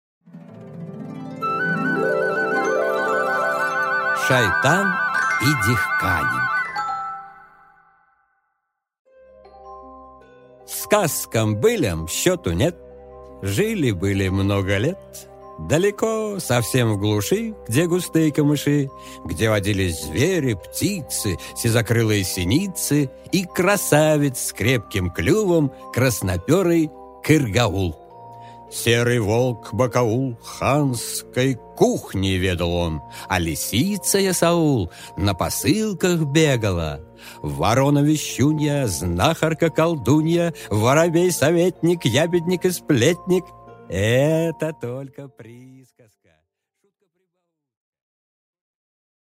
Аудиокнига Шайтан и дехканин